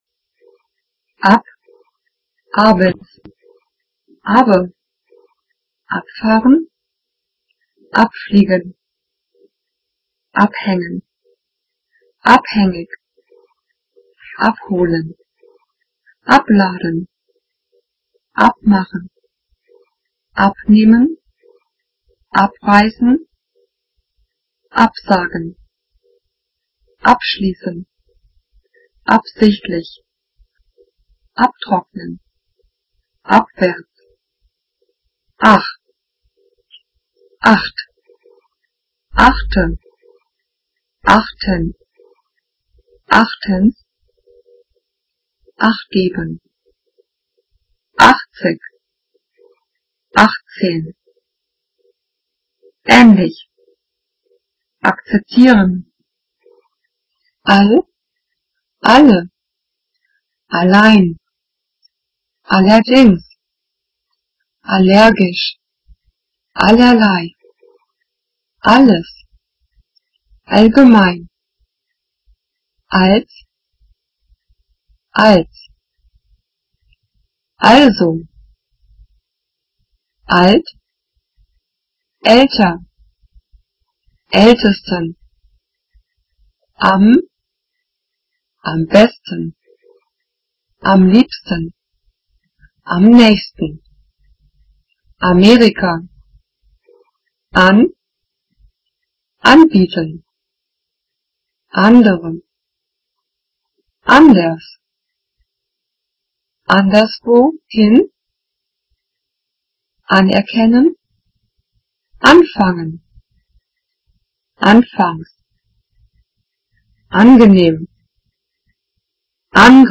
pronounciations.mp3